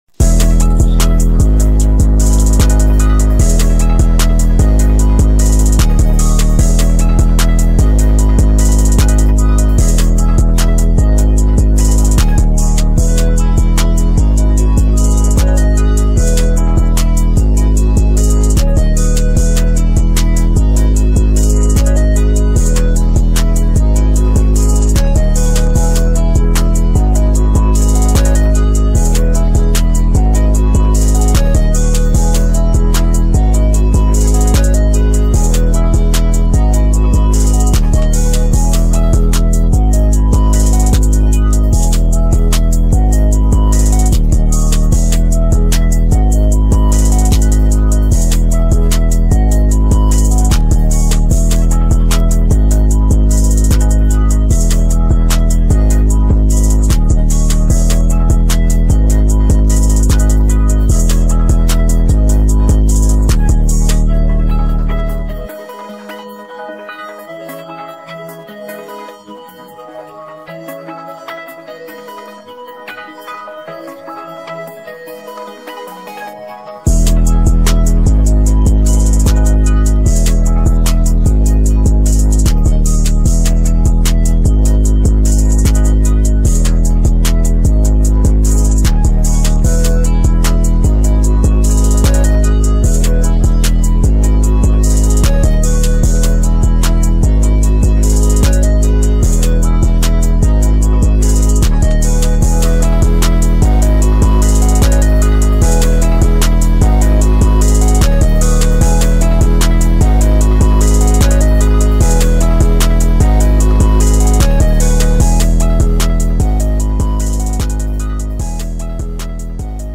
what yall think of this beat